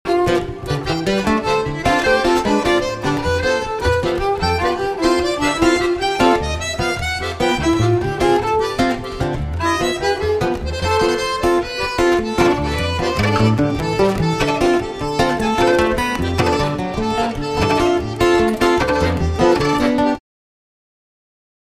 acc. git
accordion
cello